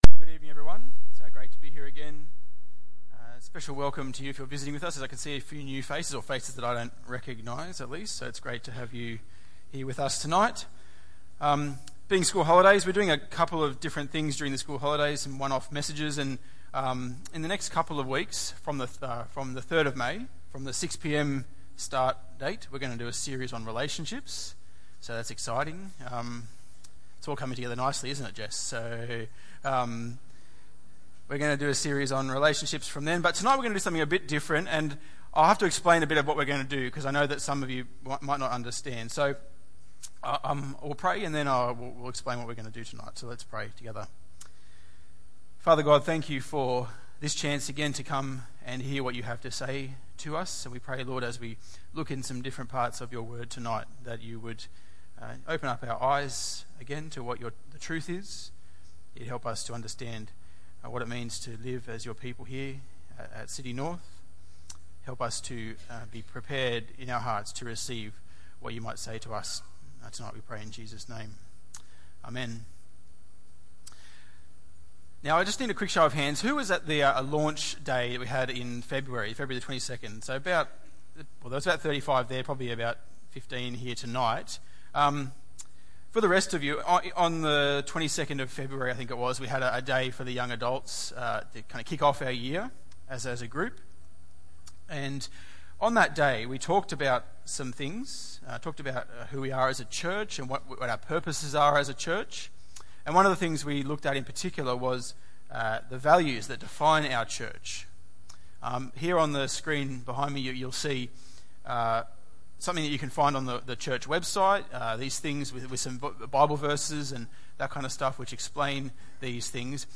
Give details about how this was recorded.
Tagged with Sunday Evening